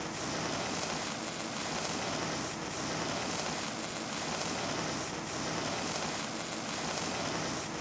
jet_boost.mp3